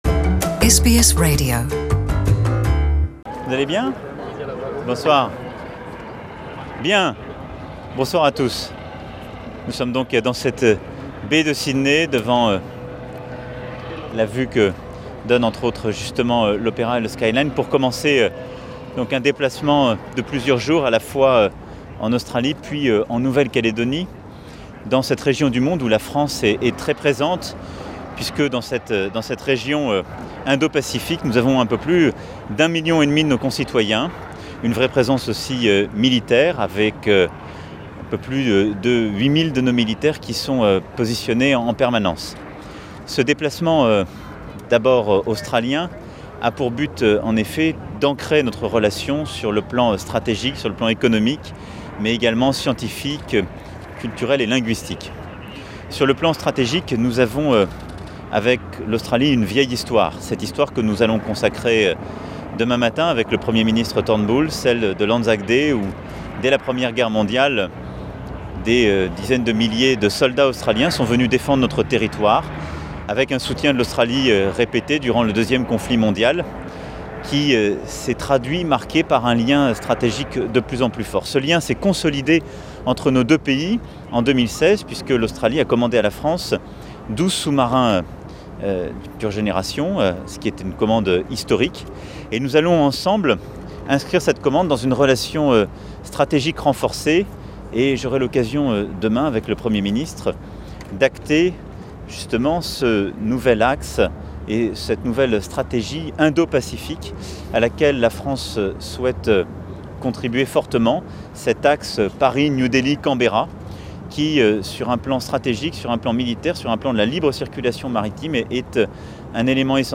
Extrait de la conference de presse du Président français en Australie.